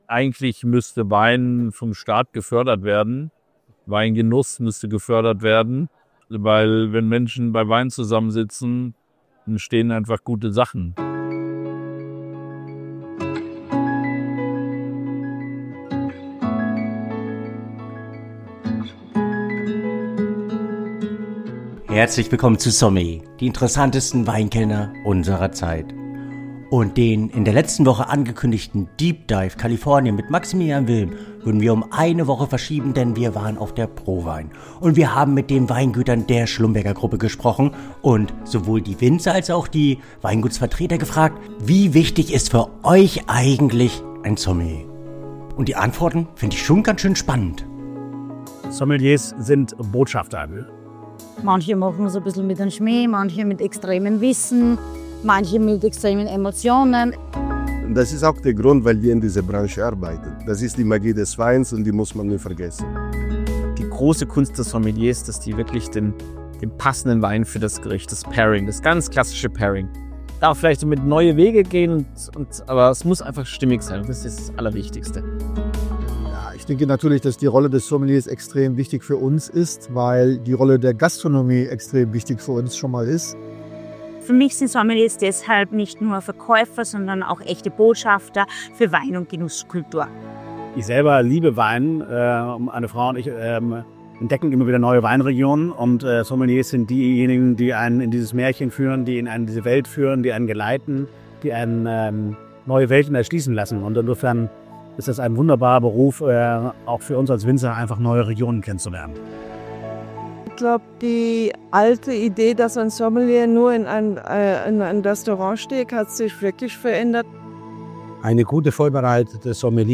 Während der ProWein 2026 in Düsseldorf haben wir mit außergewöhnlichen Winzern, Produzenten und Repräsentanten der Schlumberger-Gruppe gesprochen – und ihnen vier einfache, aber entscheidende Fragen gestellt. Wie wichtig ist der Sommelier heute wirklich?